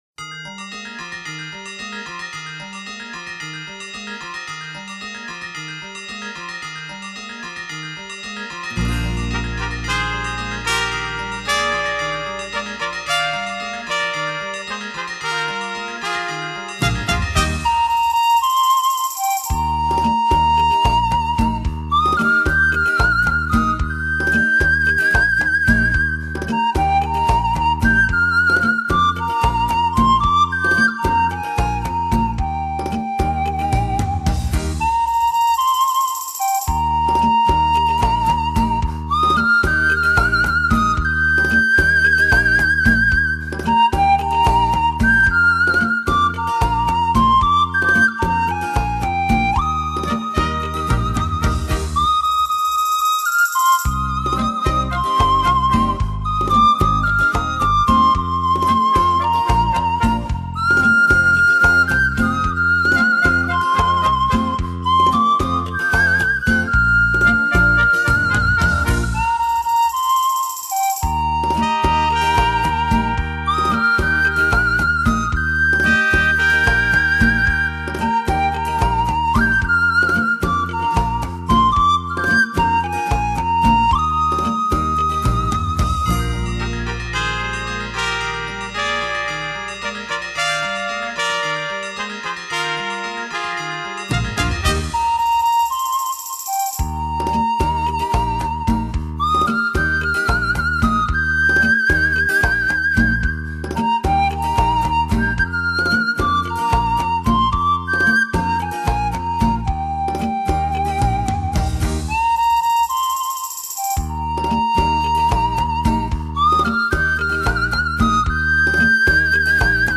用古老的中国乐器，奏异国他乡的音乐。
排箫